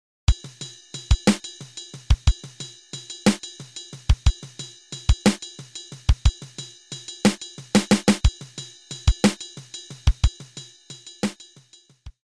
You have probably heard this ride pattern before.
This is just one example of what can be played under the ride pattern, of course.
(shuffle feel)